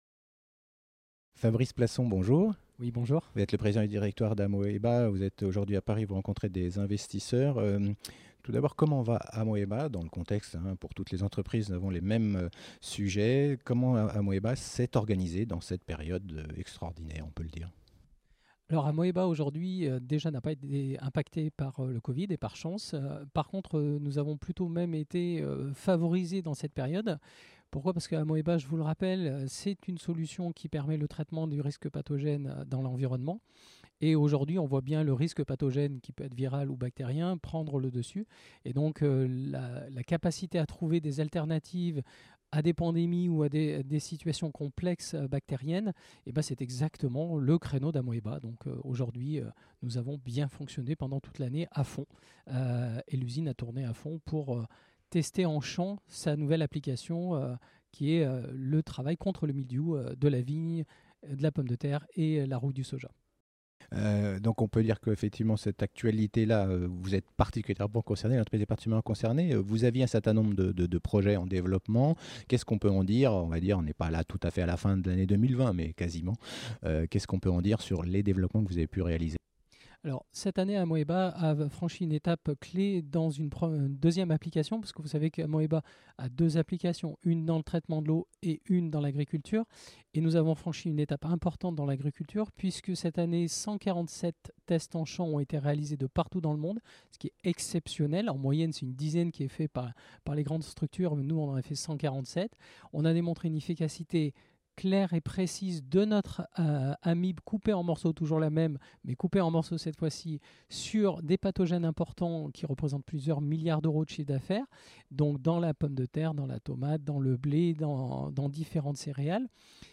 lors du Large et Midcap Event organisé par CF&B Communications